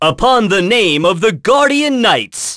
Roman-Vox_Skill6.wav